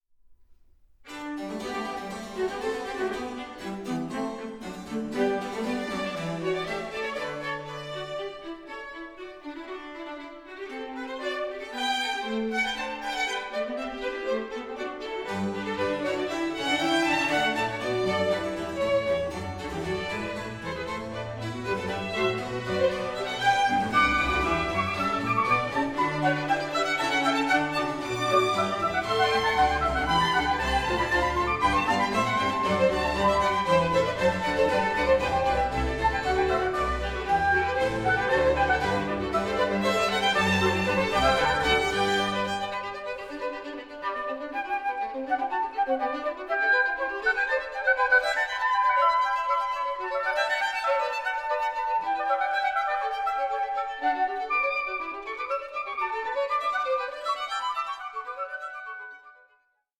for Violin, Flute & Oboe